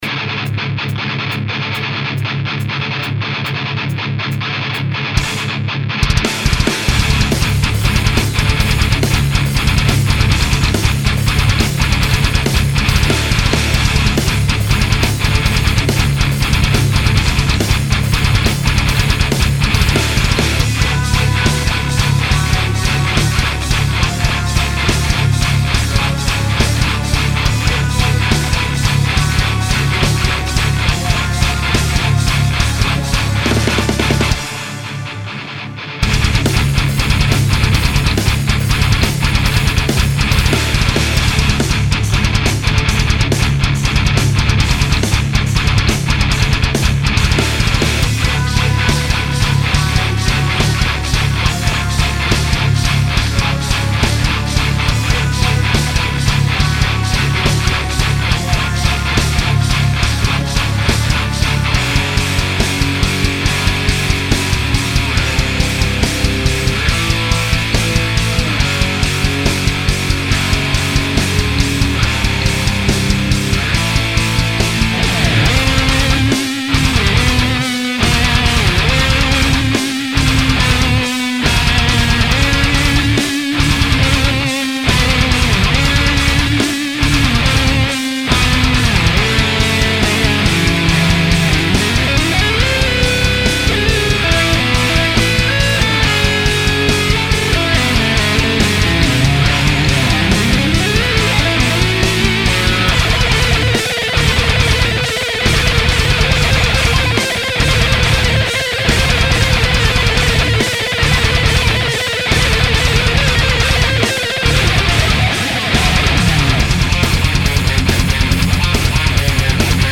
Un bundle de "gros sons" Metal pour PodXT!
Pour les impatients, voici une petite démo de l'une des banques dédiées au Rectifier du second bundle :
Son Rythmique (format l6t)
Son Guitare Synthé (sur les breaks Hardcore)(format l6t)
Son Solo (format l6t)
Zéro mastering (seulement un L2 sur le master pour le clipping), aucun effet sur les pistes de gratte dans le mix, c'est le son du podXT brut de déco